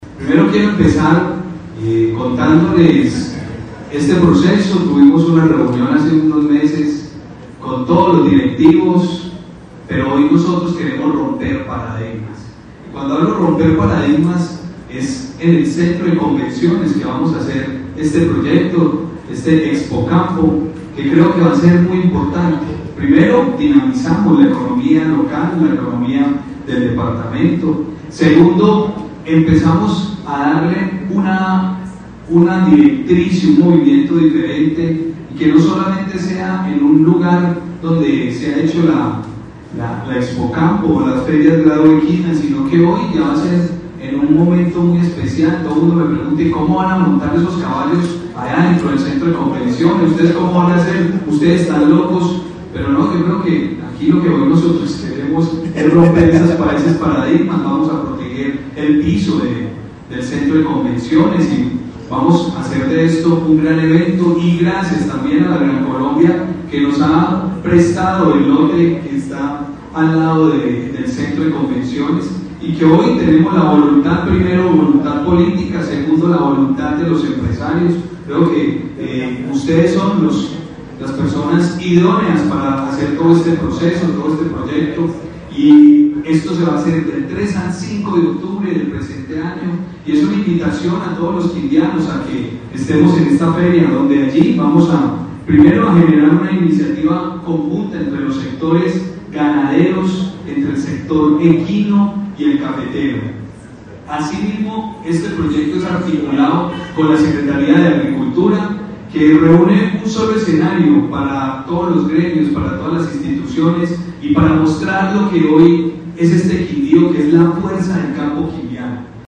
Audio del gobernador Juan Miguel Galvis Bedoyagobernador Juan Miguel Galvis Bedoya: